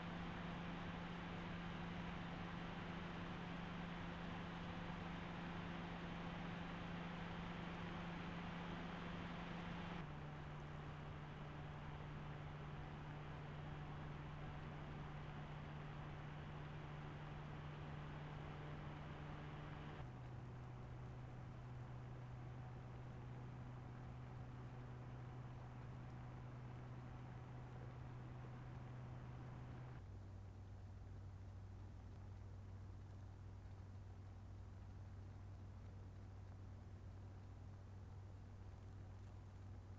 Aufnahme weiterer Kühler (anderes Verfahren, anderes Mikrofon)
Noctua NH-D15S: Lautstärke von 100 bis 49 Prozent PWM (31 dB)